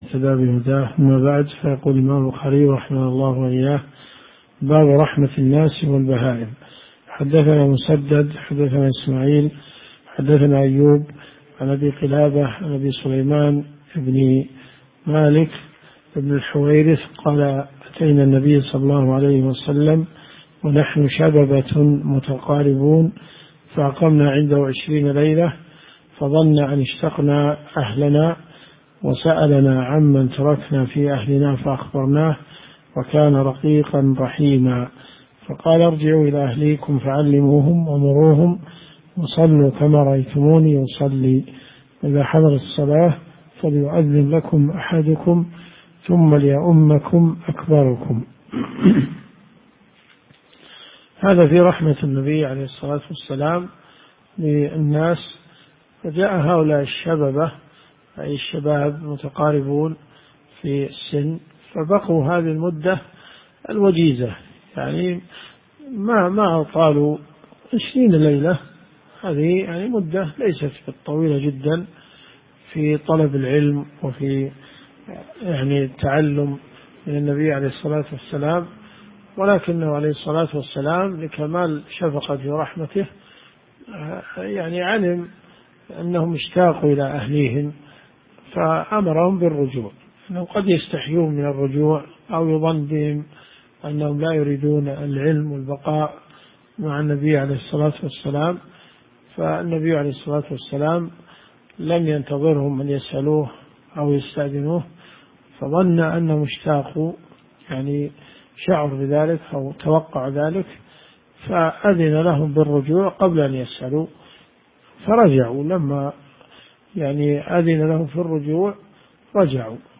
دروس صوتيه ومرئية تقام في جامع الحمدان بالرياض
صحيح البخاري . كتاب الأدب - من حديث 6008 -إلى- حديث 6017 - الثلاثاء . شرح مأخوذ من مكان آخر ليجبر السقط .